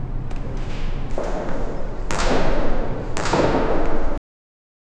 Rapid footsteps echoing on concrete; faint screech of hangar doors opening; soft rustling of coat in motion; distant hum of airplane engines; expansive, industrial reverb of the hangar.
rapid-footsteps-echoing-o-brqfowuh.wav